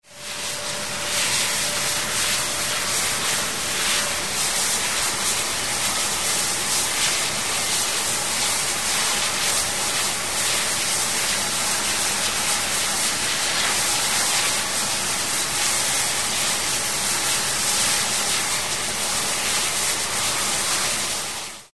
Звуки пара